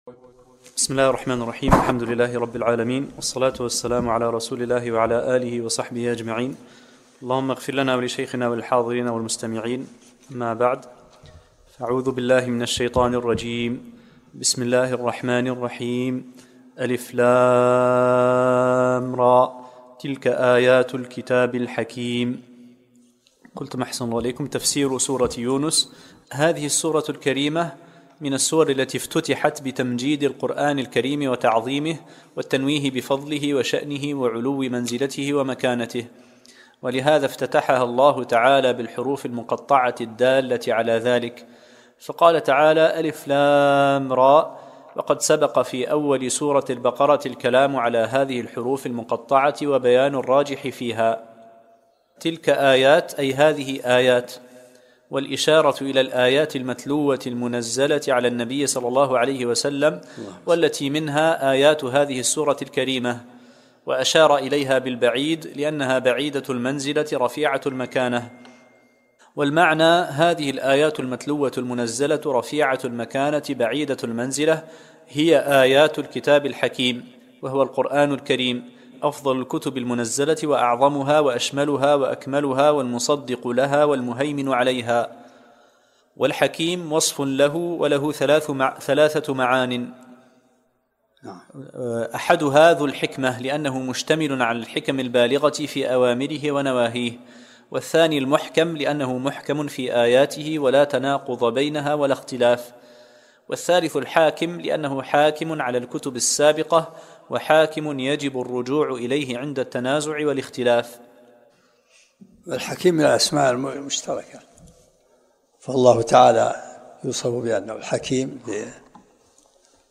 الدرس الأول من سورة يونس